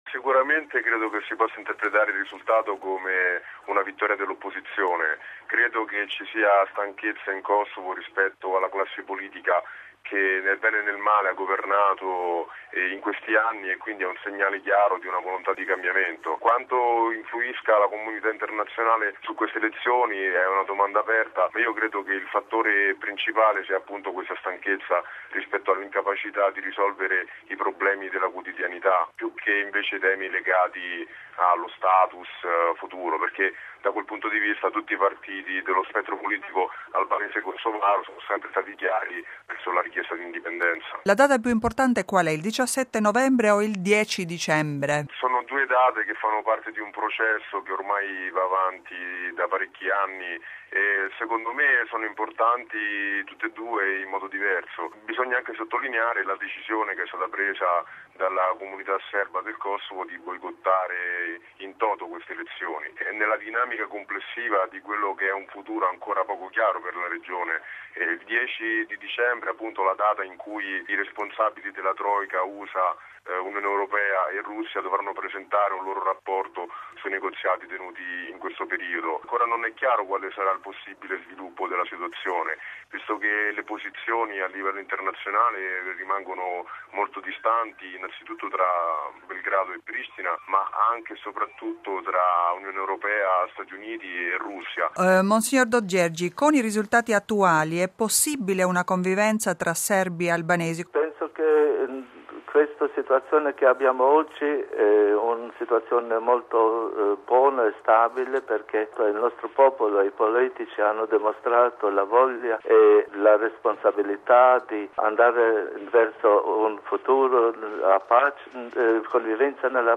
Bollettino del Radiogiornale della Radio Vaticana Anno LI no. 323